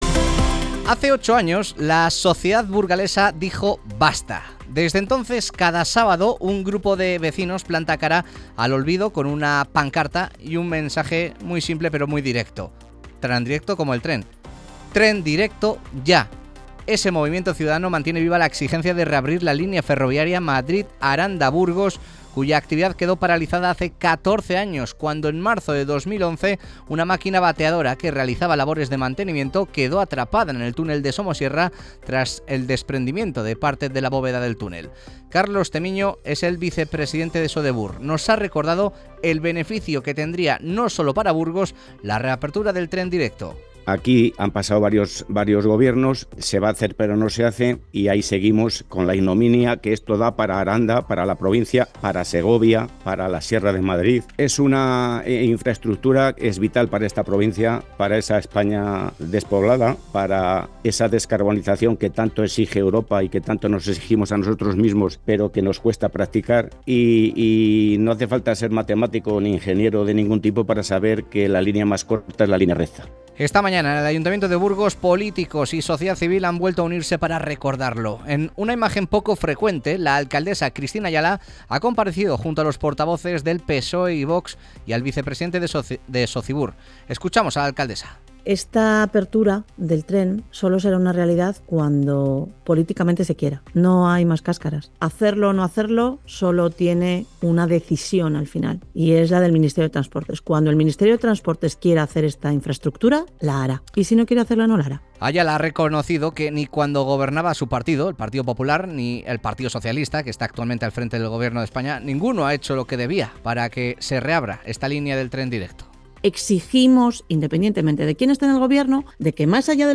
Comparecencia de la alcaldesa de Burgos, Cristina Ayala